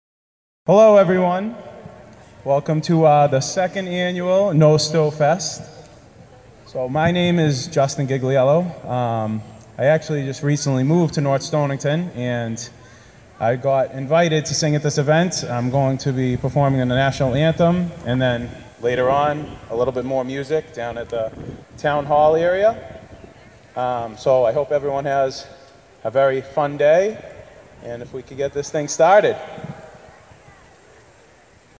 This music was recorded at the North Stonington Middle School-High School grounds in North Stonington, CT using an Android Galaxy 7 Edge on September 23rd, 12:00PM-4:00PM.